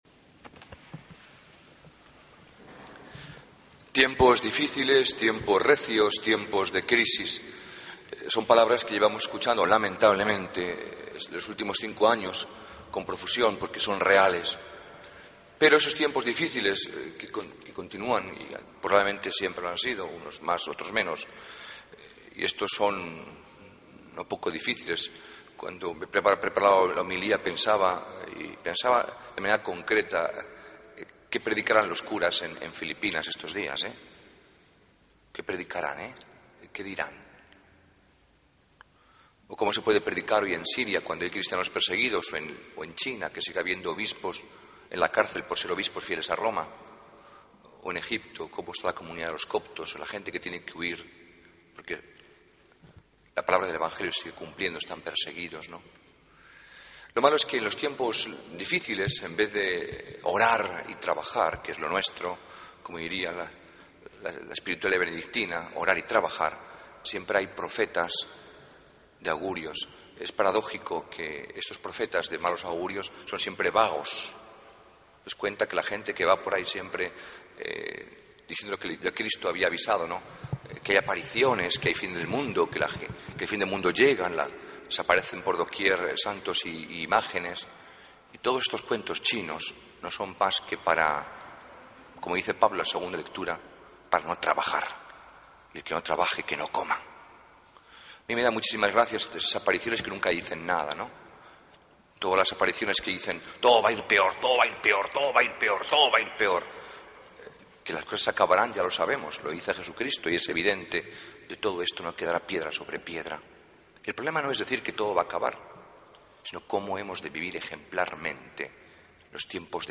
Homilía del domingo 17 de noviembre de 2013